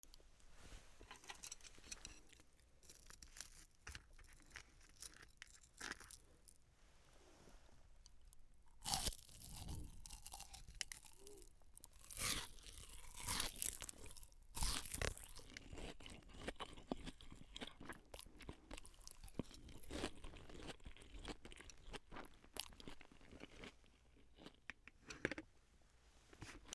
U87Ai